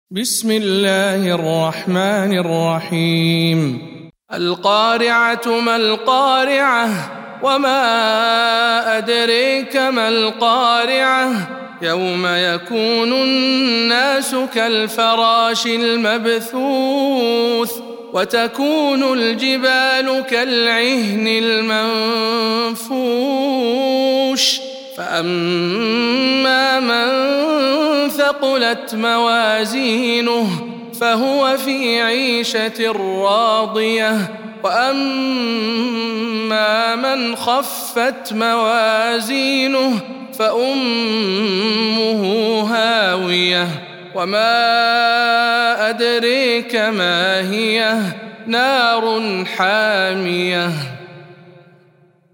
سورة القارعة - رواية إسحاق عن خلف العاشر